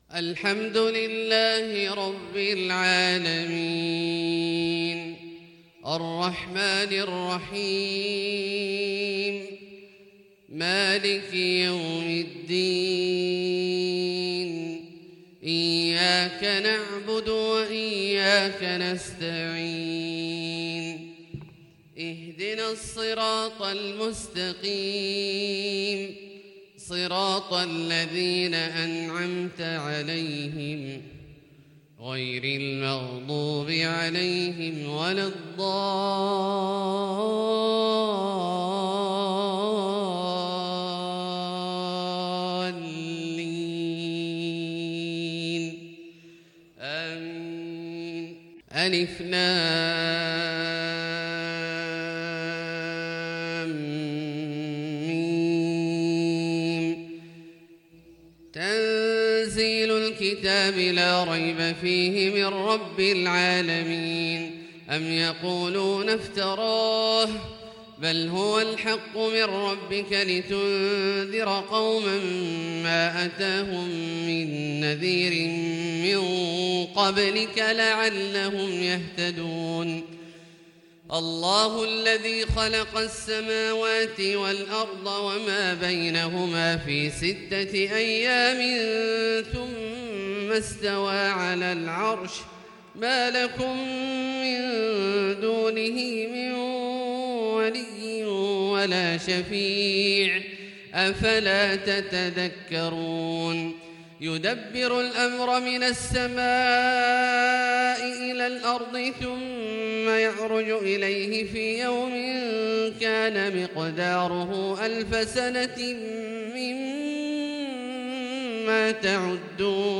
صلاة الفجر للقارئ عبدالله الجهني 29 شوال 1442 هـ
تِلَاوَات الْحَرَمَيْن .